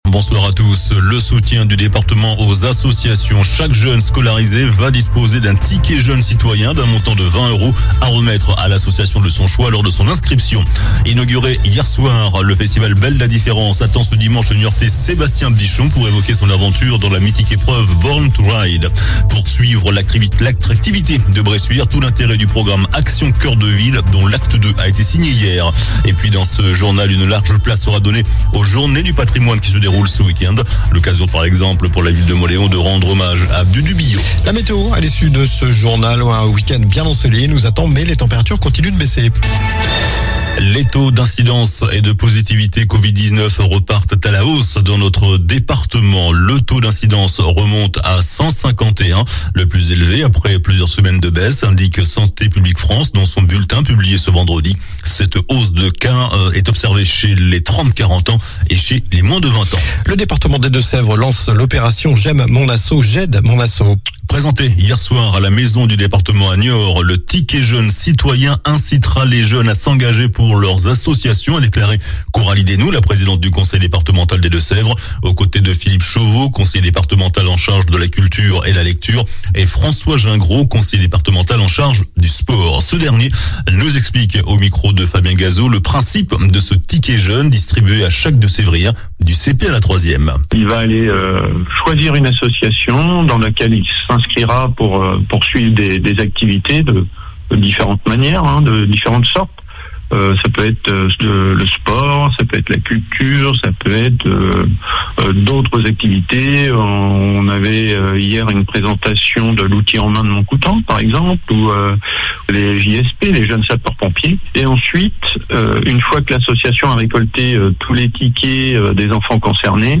JOURNAL DU VENDREDI 16 SEPTEBRE ( SOIR )